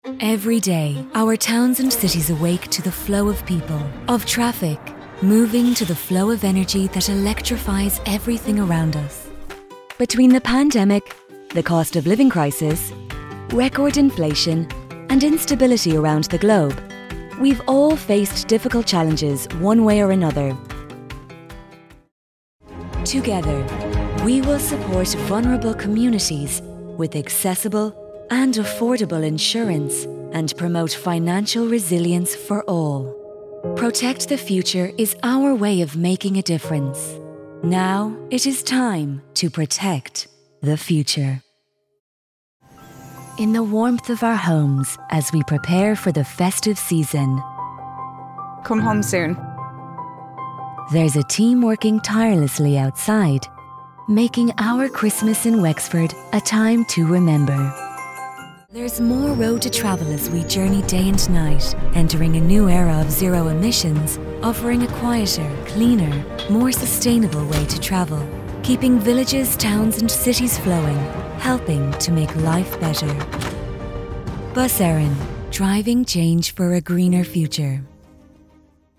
English (Irish)
Commercial Demo
Neumann TLM 103